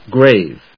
/gréɪv(米国英語), greɪv(英国英語)/